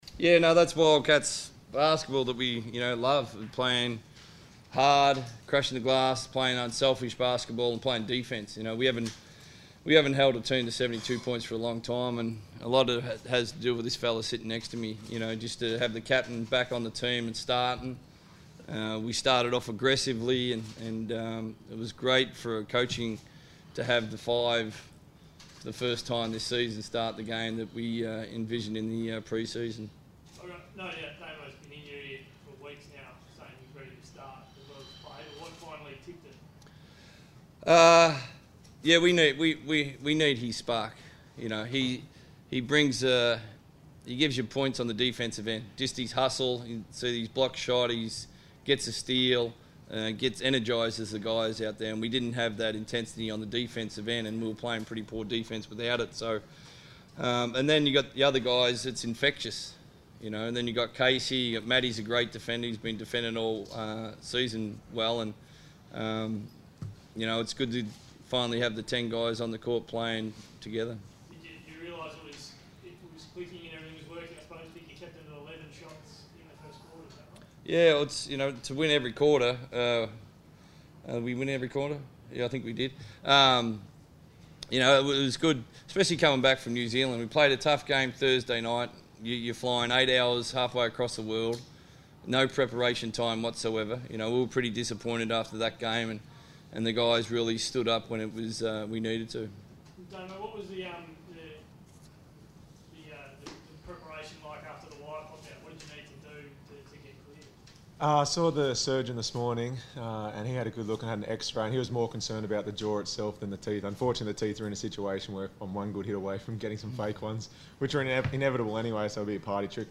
speak to the media following the Perth Wildcats win over the Adelaide 36ers.